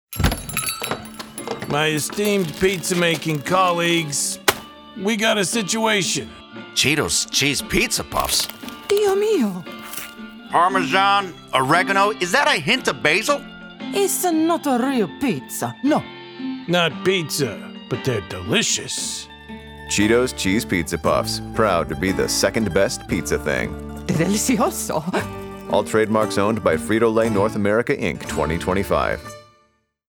Commercial Voice Overs